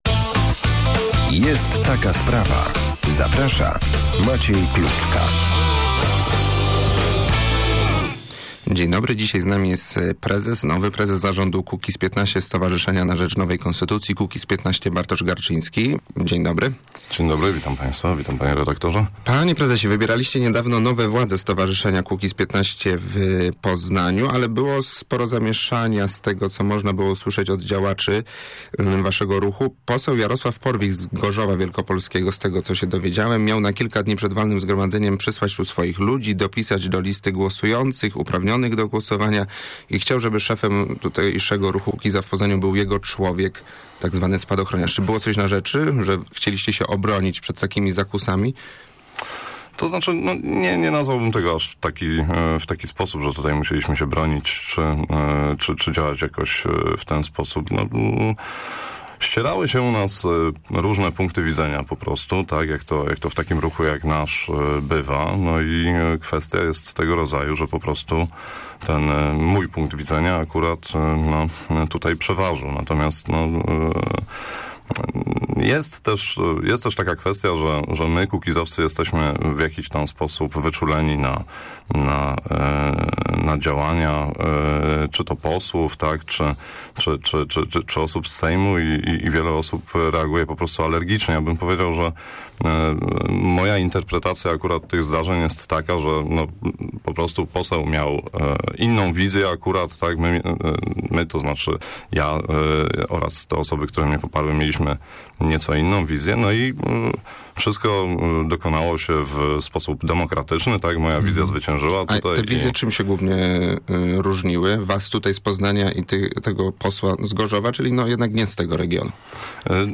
w porannej rozmowie Radia Merkury